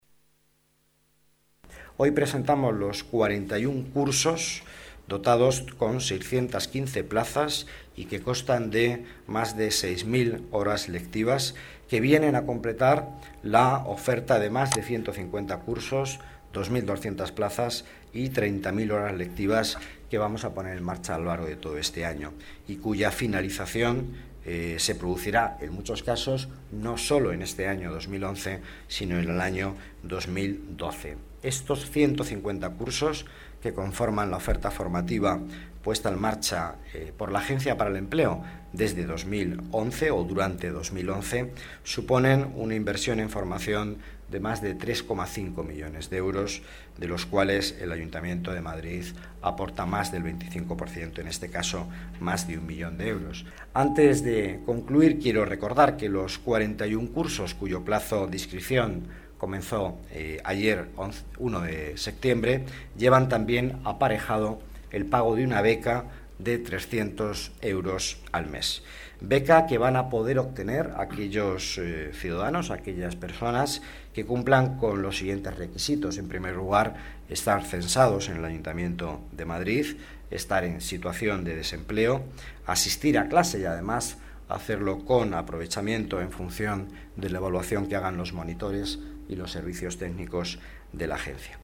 Nueva ventana:Miguel Ángel Villanueva, delegado de Economía, Empleo y Participación Ciudadana habla de los cursos de la Agencia de Empleo
Miguel Ángel Villanueva, delegado de Economía, Empleo y Participación Ciudadana habla de los cursos de la Agencia de Empleo Declaraciones de Miguel Ángel Villanueva sobre las becas de los cursos de la Agencia de Empleo